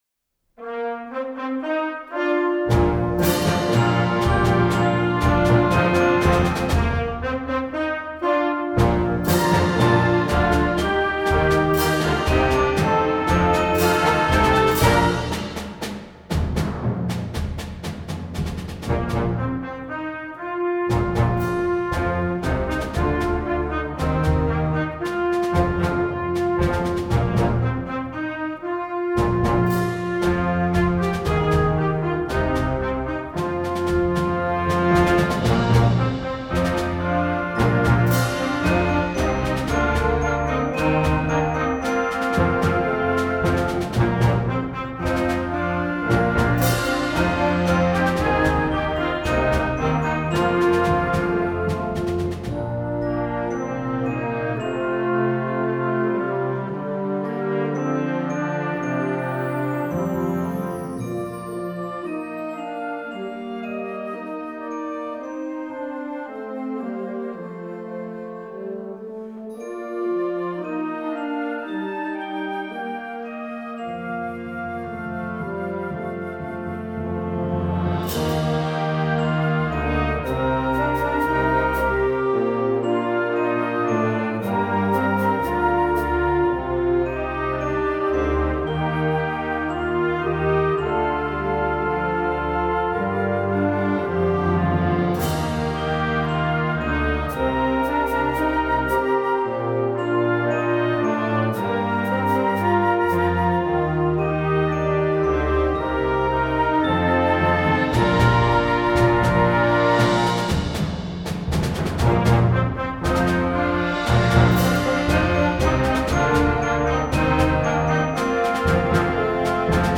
Instrumental Concert Band Concert/Contest
Concert Band